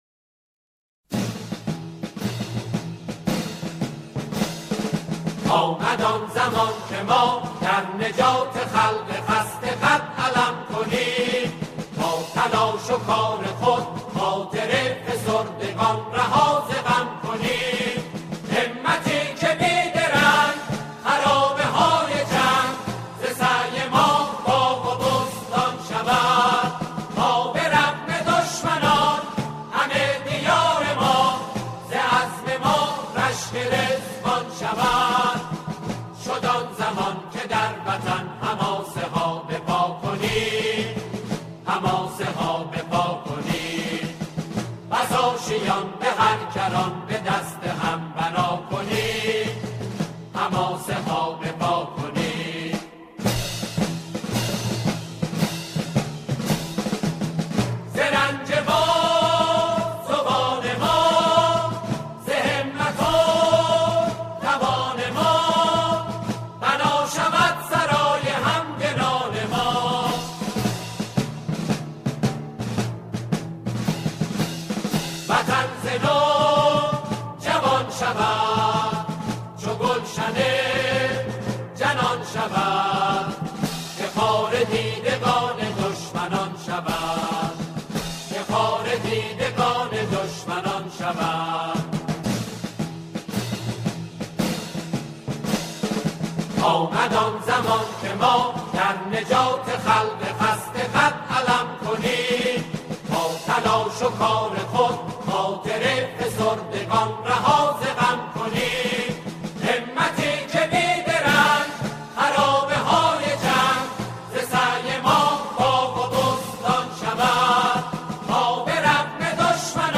سرود قدیمی